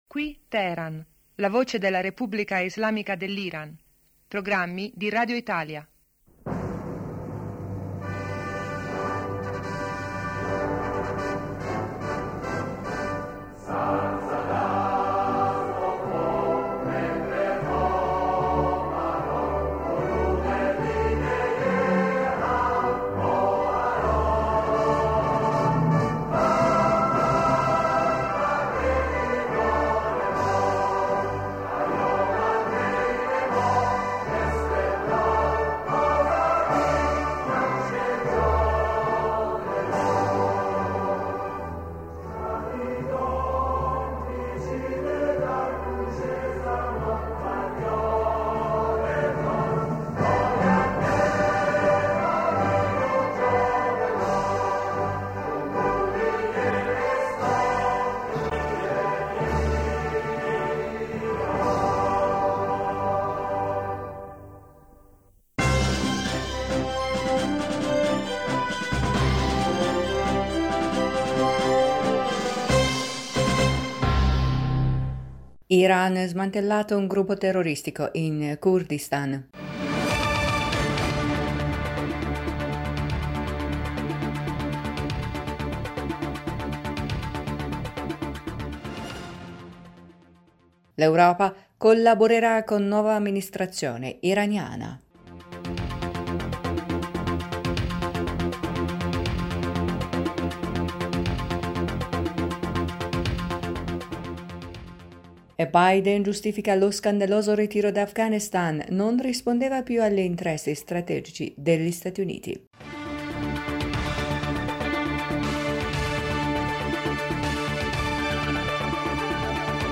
Ecco i titoli più importanti del nostro radiogiornale:1-Biden giustifica lo scandaloso ritiro da Afghanistan: ‘non rispondeva più agli interessi strategici...